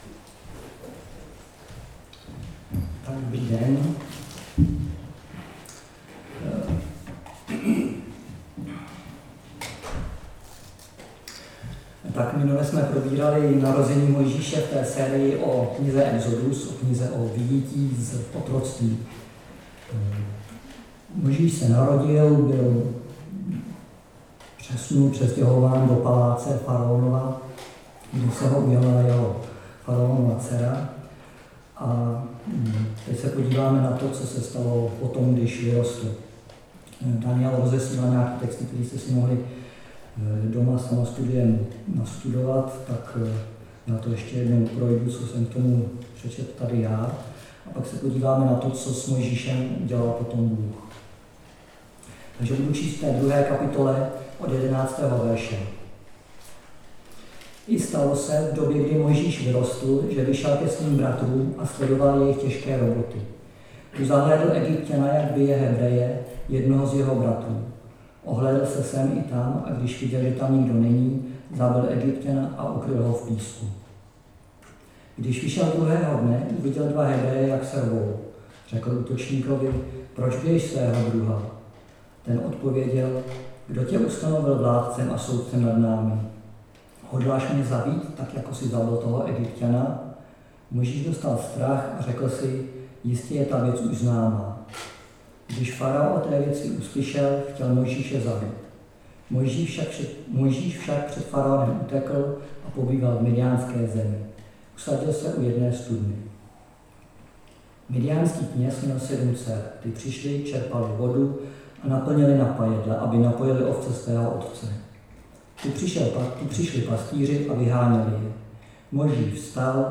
Chvály
Nedělní bohoslužby